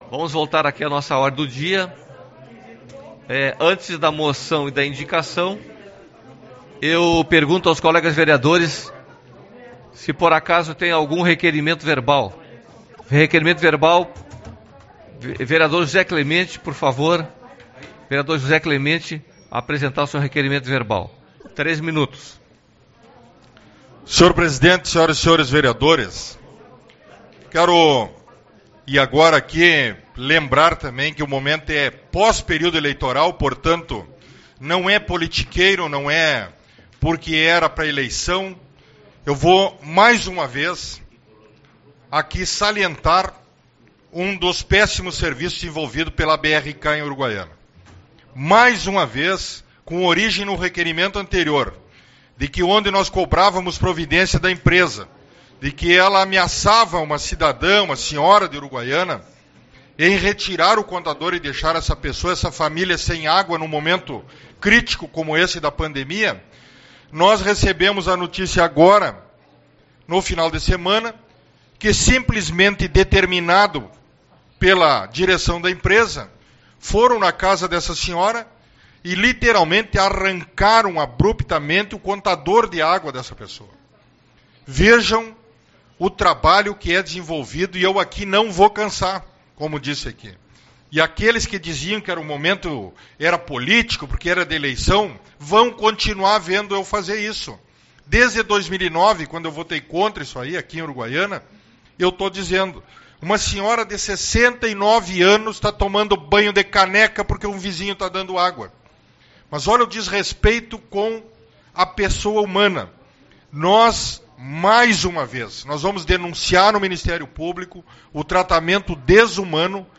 17/11 - Reunião Ordinária